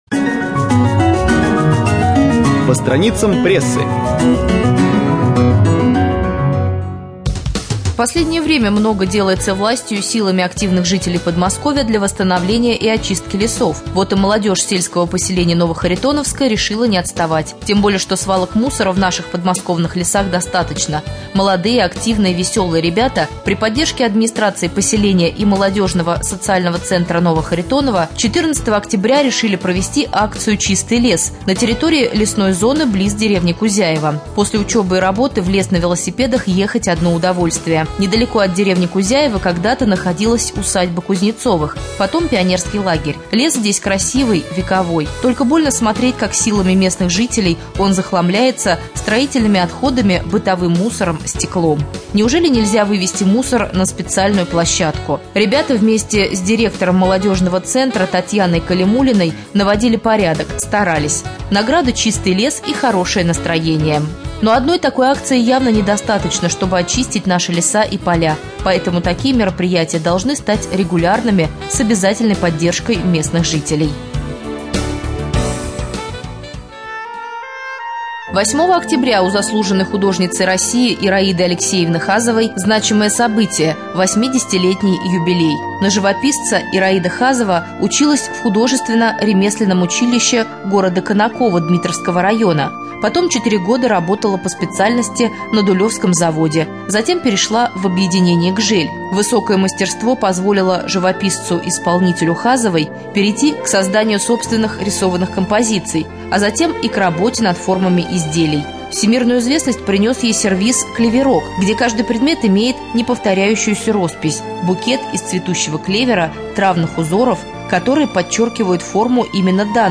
29.10.2013г. в эфире раменского радио - РамМедиа - Раменский муниципальный округ - Раменское